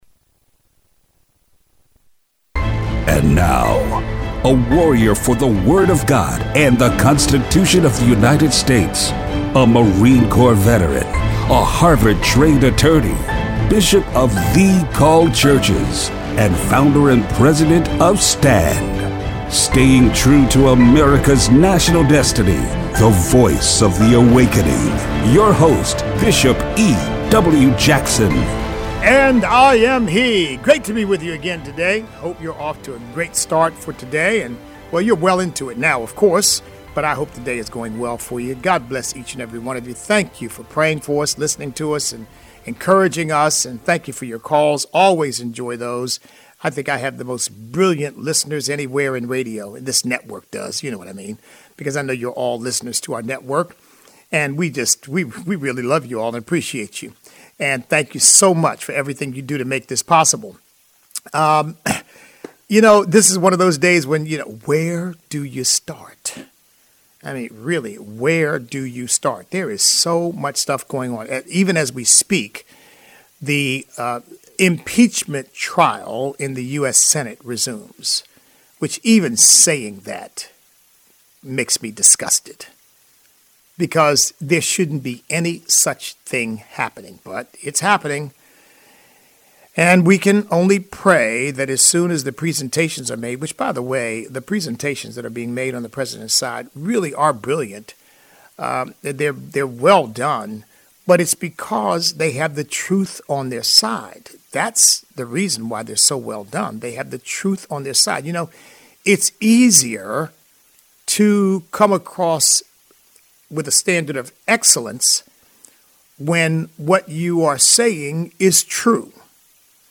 Black Lives Matter curriculum being taught in our public schools. Listener call-in.